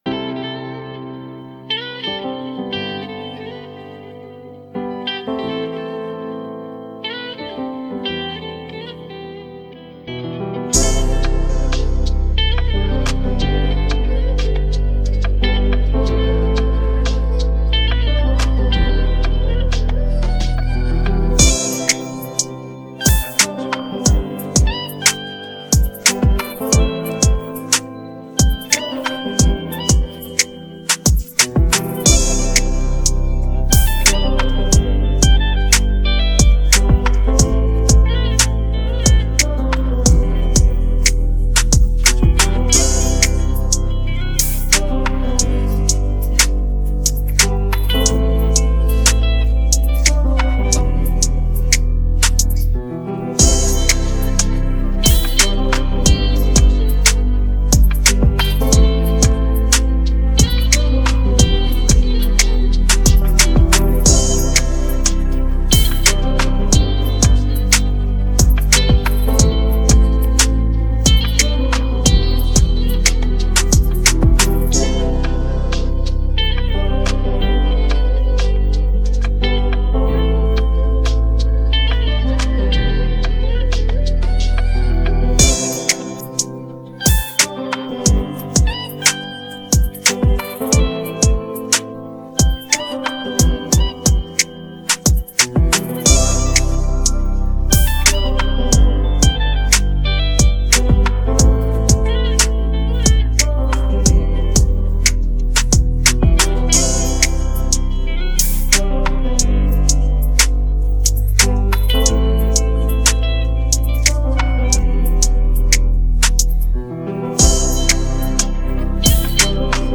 Hip hopPop